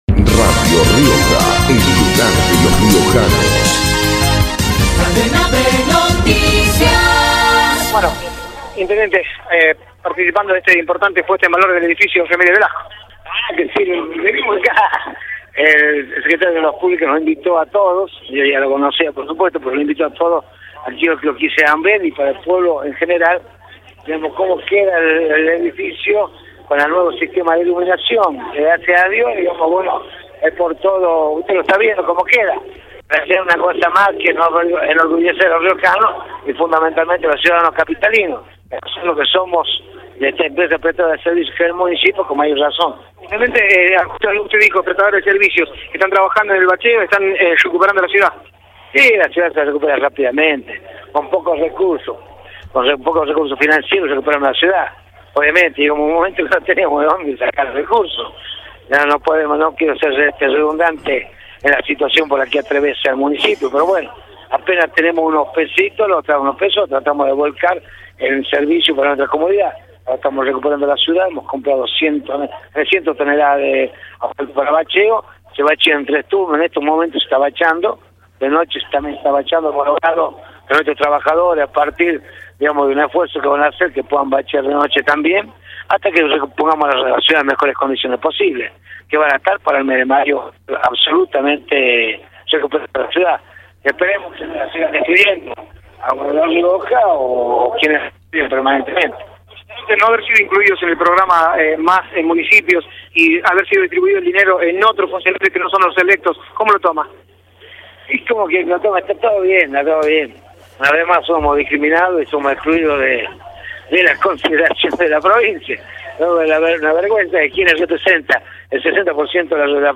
Ricardo Quintela, intendente, por Radio Rioja